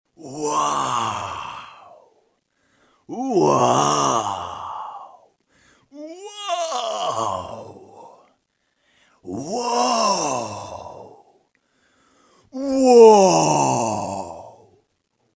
Звуки вау
Мужчина очень удивляется и восклицает wow